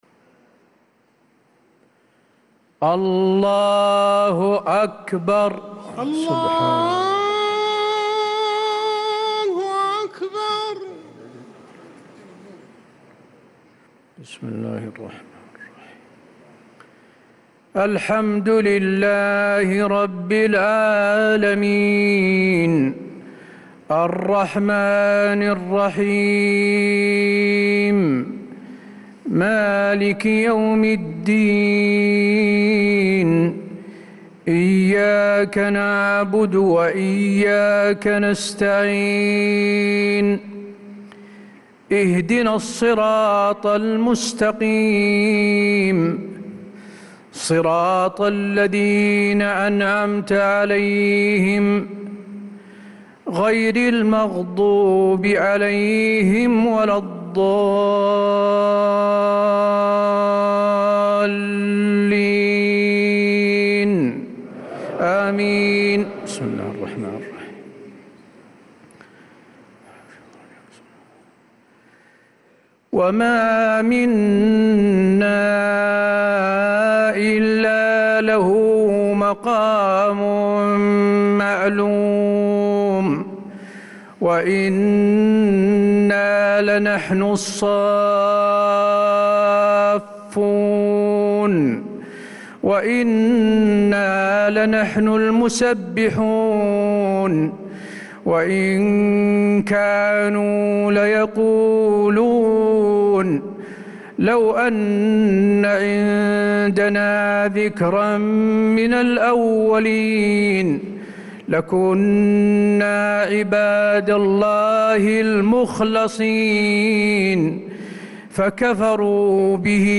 صلاة العشاء للقارئ حسين آل الشيخ 27 محرم 1446 هـ